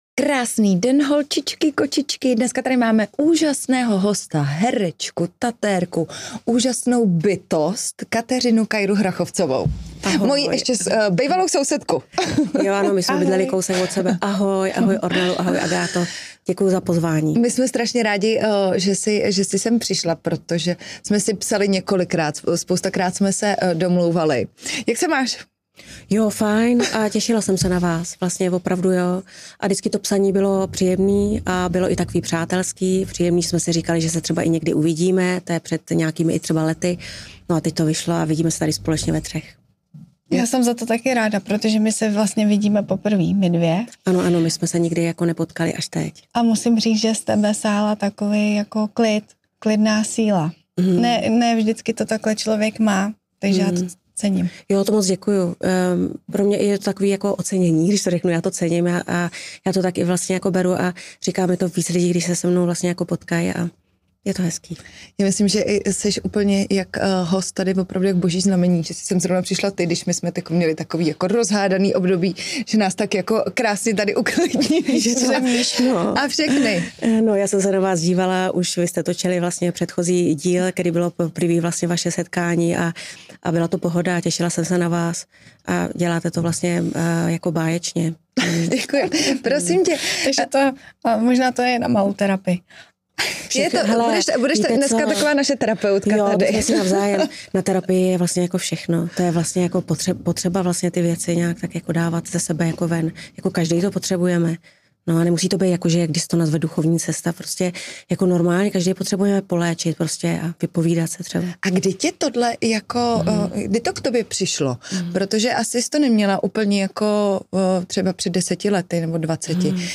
Její cesta za sebepoznáním a životní esprit srší energií…a tento rozhovor je opravdu obsahově velmi zajímavý.